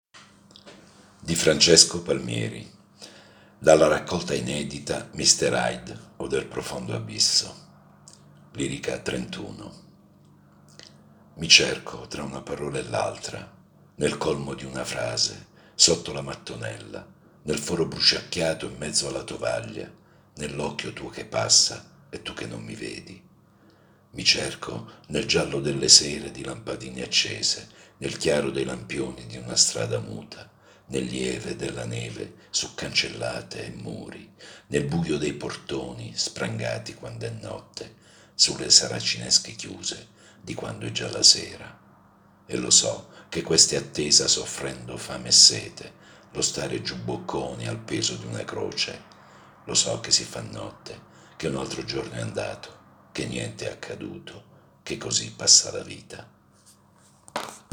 Solo un’avvertenza: la voce narrante è quella di un lettore comune e non l’espressione professionale di un attore, così come l’ambiente operativo che non è uno studio di registrazione.